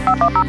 As I said, only audio data can be carried by this point to point radio link, so the solution is to have a 3 note multi-frequency tone (like the dialing tones on telephones) that the RDS data generator receives and recognises, changing the flag as a result, hearing the tones again causes it to switch back.
Click here to hear that set of tones (22k/b Wave file) Note that the tones in this sample have a jingle in the background.
rdstraffictone.wav